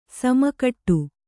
♪ sama kaṭṭu